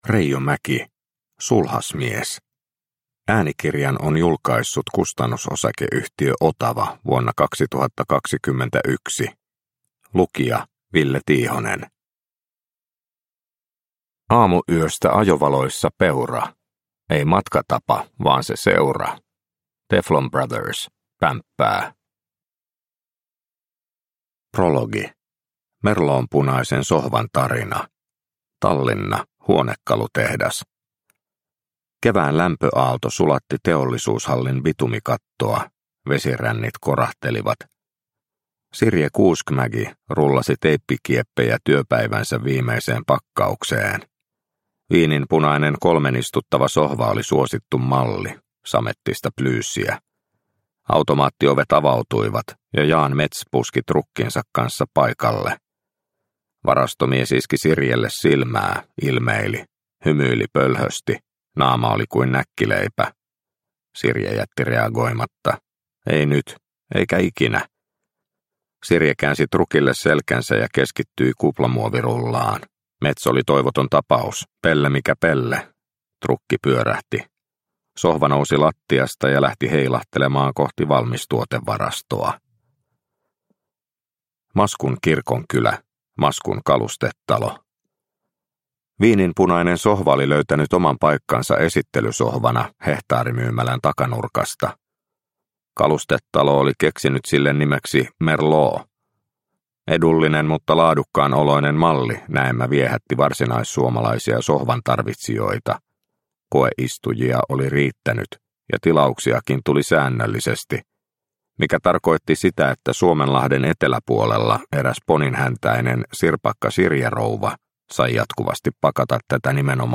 Sulhasmies – Ljudbok – Laddas ner